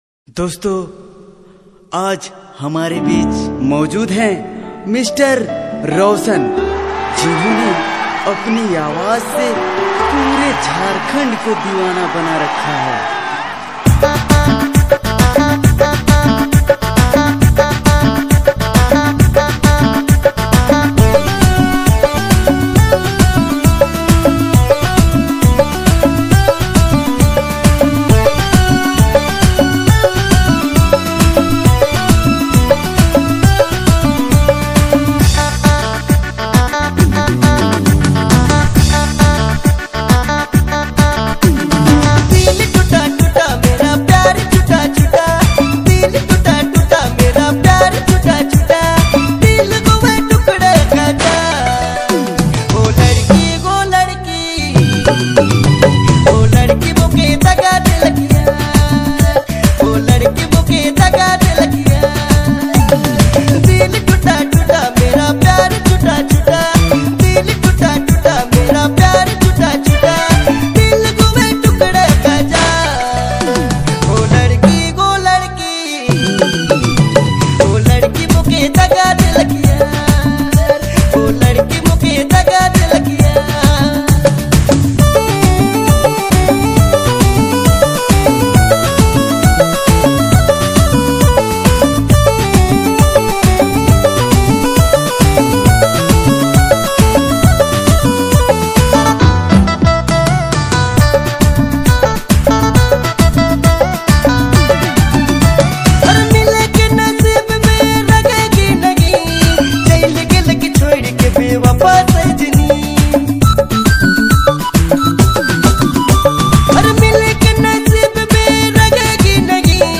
emotional